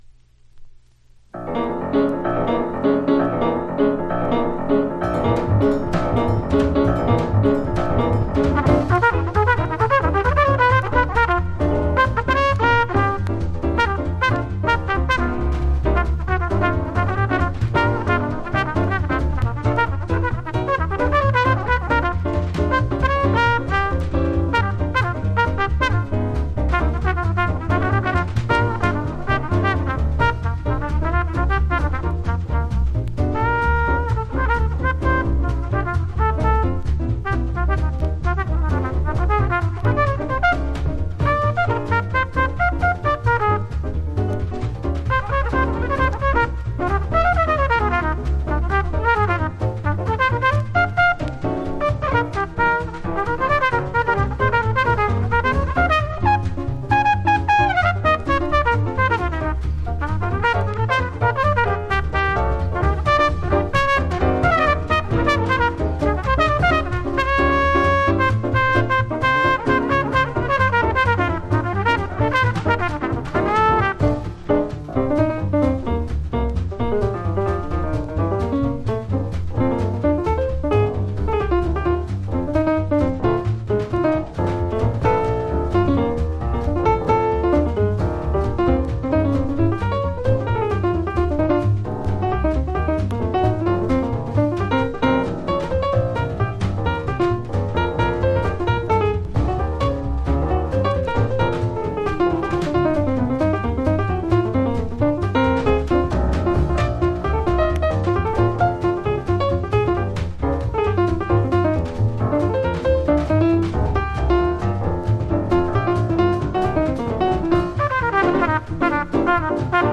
（プレス・小傷によりチリ、プチ音ある曲あり）
Genre US JAZZ